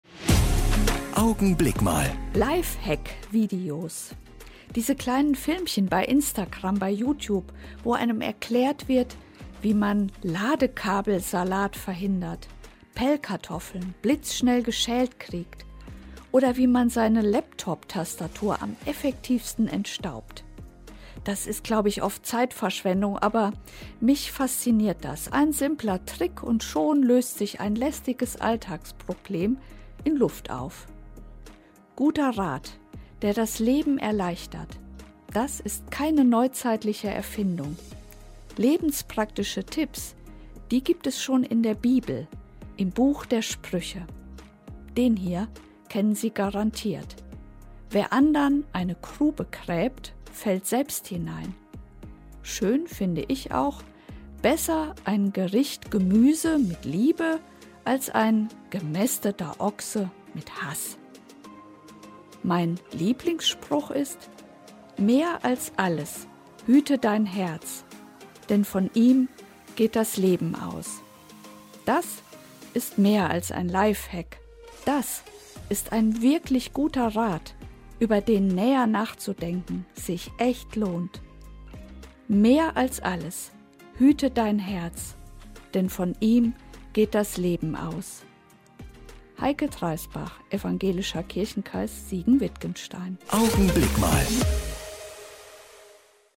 Augenblick Mal - die Kurzandacht im Radio
Jeden Sonntag gegen halb neun bei Radio Siegen zu hören: Die Kurzandacht der Kirchen (evangelisch und katholisch) - jetzt auch hier im Studioblog zum Nachhören.